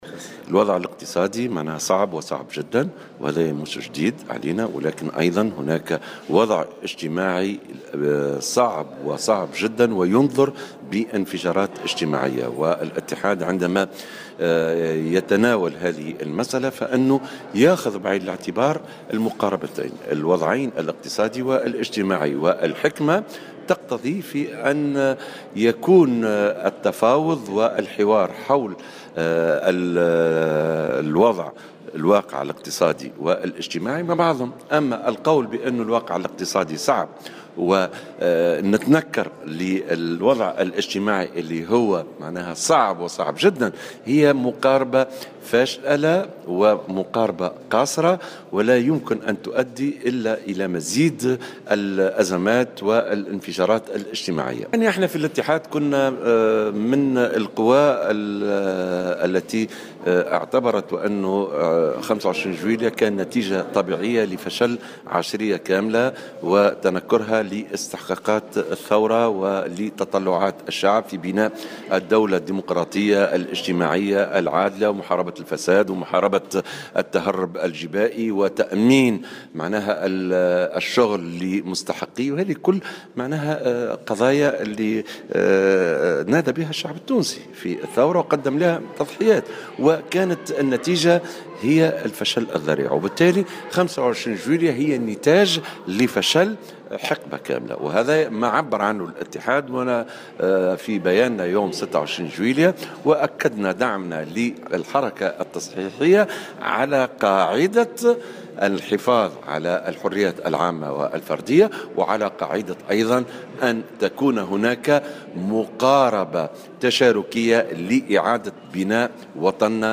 وأضاف في تصريح اليوم لـ"الجوهرة أف أم" على هامش إشرافه على مؤتمر الشباب و المرأة العاملة بسوسة، أنه عندما تتناول المنظمة الشغيلة هذه المسألة، فإنها تأخذ بعين الاعتبار الوضعين الاجتماعي والاقتصادي".